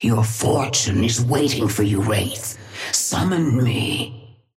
Sapphire Flame voice line - Your fortune is waiting for you, Wraith. Summon me.
Patron_female_ally_wraith_start_10.mp3